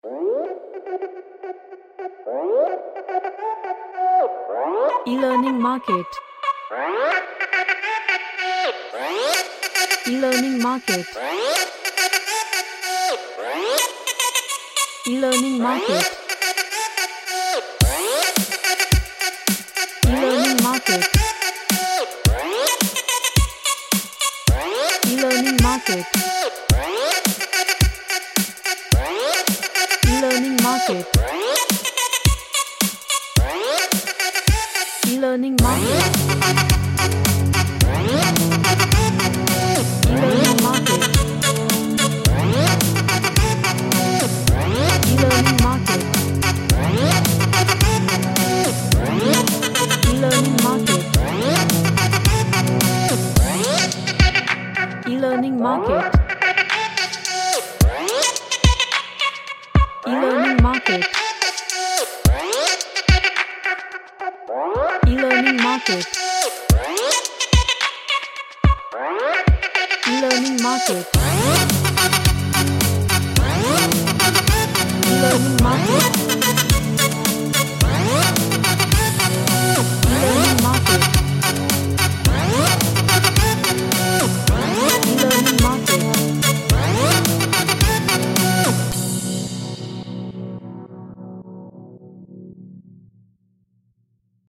A futuristic sounding vocalic track
Magical / MysticalChill Out